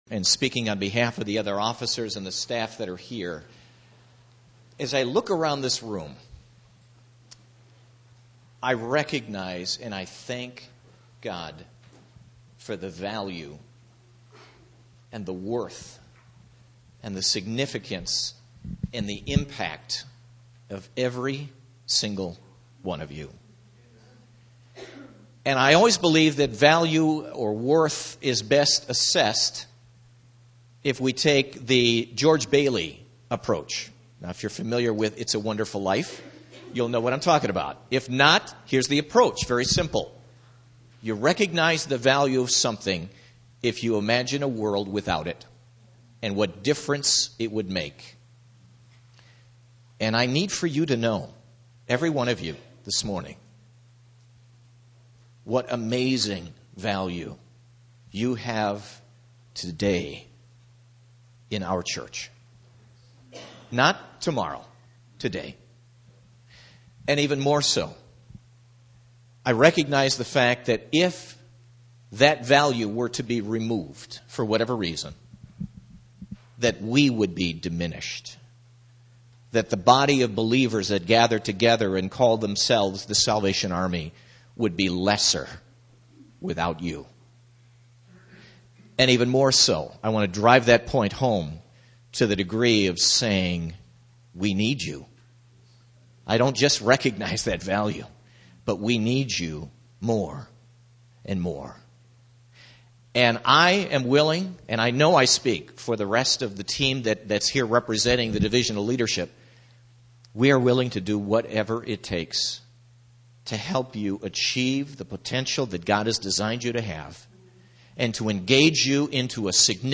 Ekklesia 2011 was such a great weekend, and I know you were challenged, strengthened, and changed.